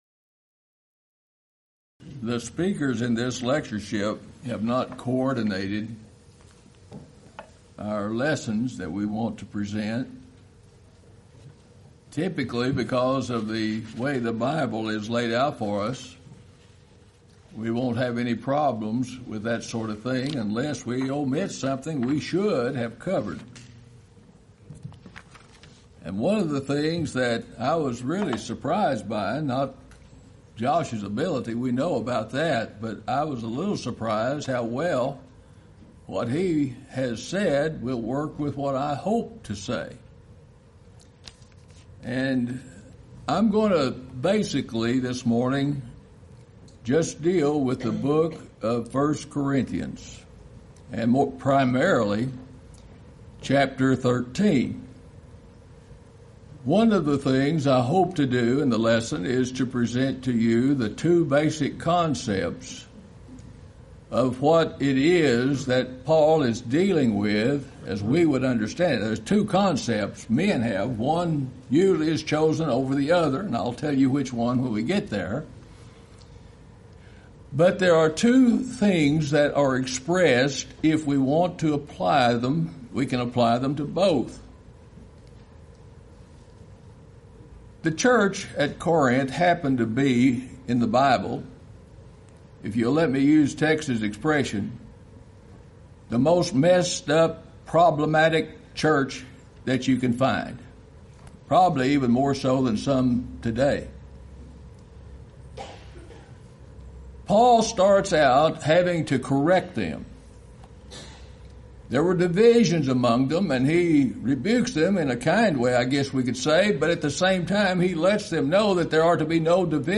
Event: 26th Annual Lubbock Lectures Theme/Title: God is Love